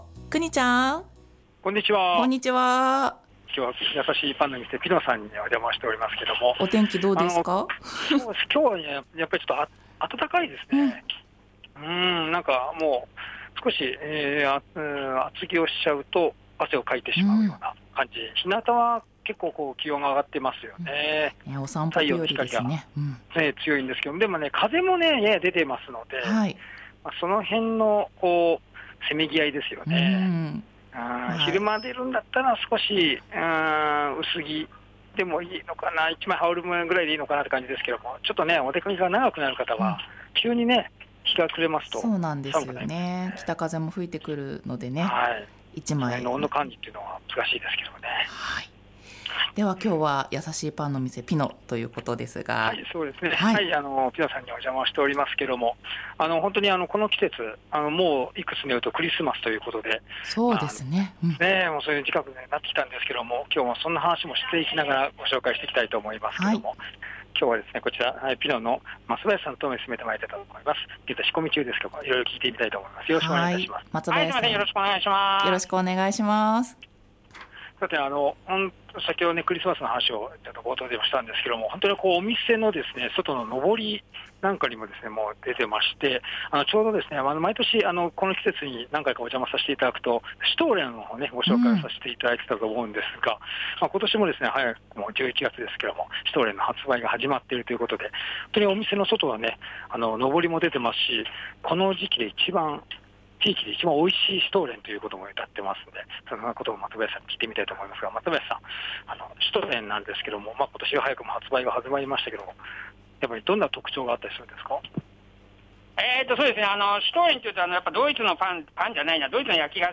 午後のカフェテラス 街角レポート
国領駅から歩いて５分狛江通沿いにあります やさしいパンの店PINOさんにお邪魔しました。